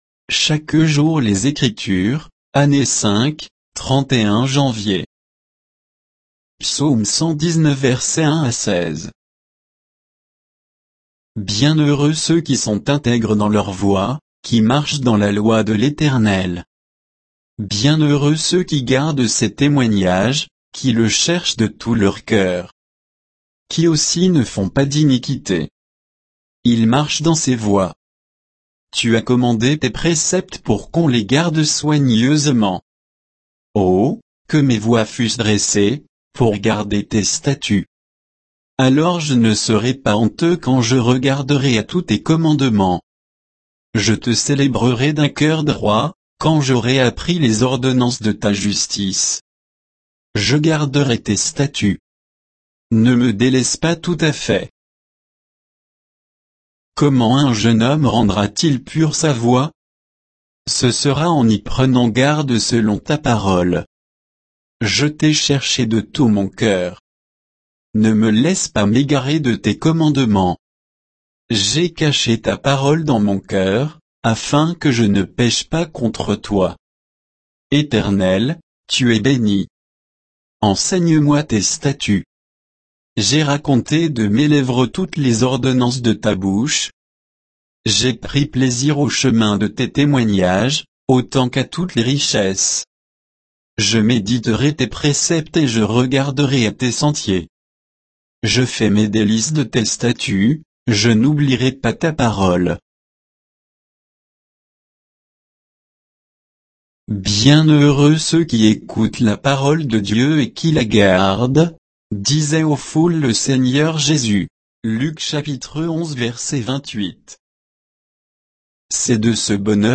Méditation quoditienne de Chaque jour les Écritures sur Psaume 119